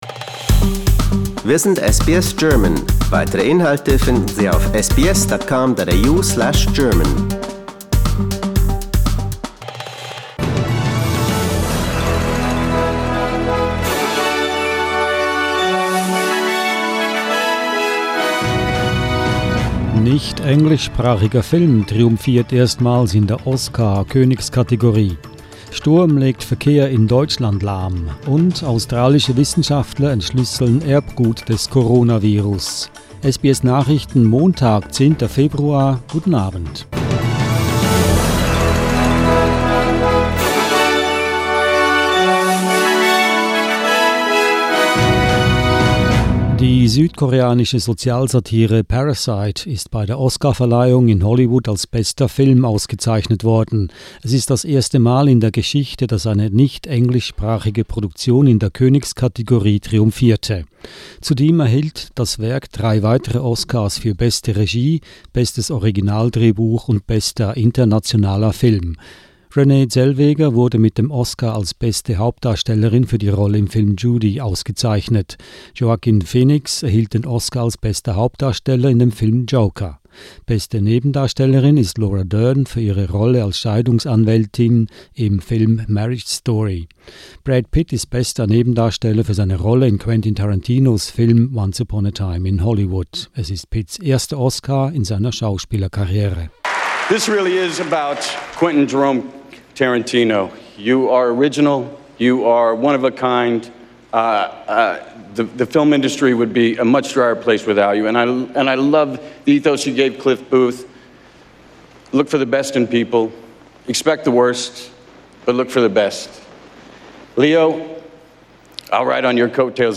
SBS Nachrichten, Montag 10.2.20